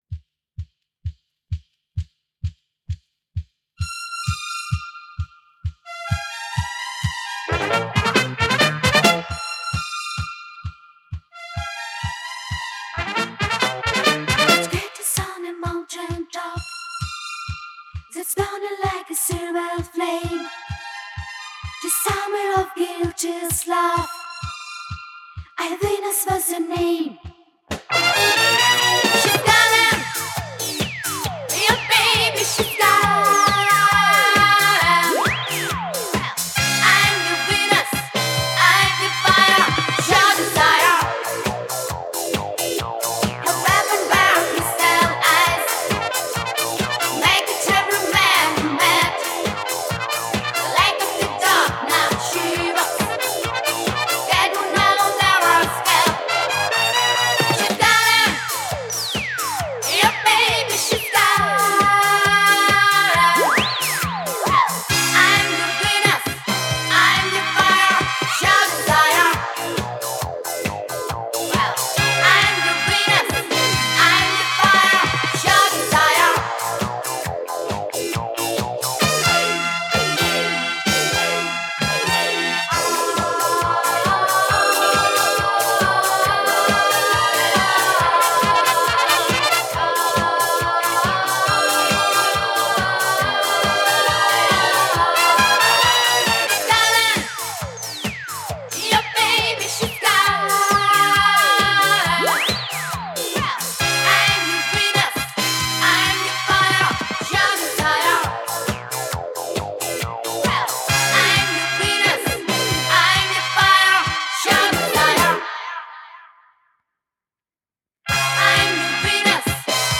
Жанр: Rock, Pop
Формат: 2LP In 1CD, Stereo, Album, Remastered, Reissue
Стиль: Vocal, Pop Rock, Schlager